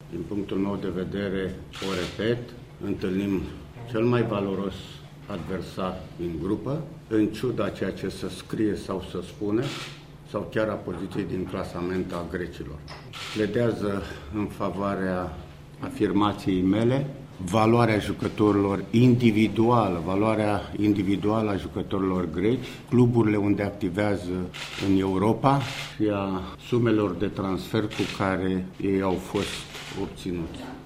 Selecționerul primei reprezentative, Anghel Iordănescu, a vorbit din nou despre forța Greciei, singura echipă din grupă care a fost prezentă la Campionatul Mondial din 2014, tocmai după un baraj în care a eliminat România: